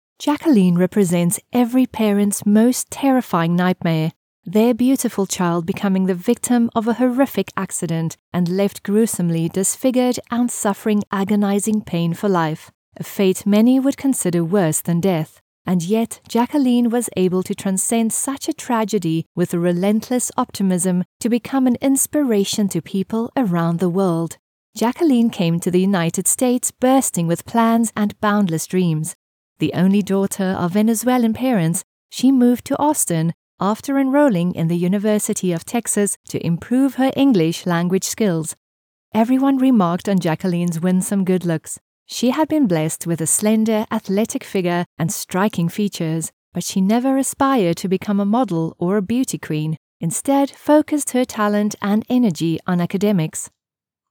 English (South African)
Audiobooks
Behringer C1 Condenser microphone
Sound-proofed room
HighMezzo-Soprano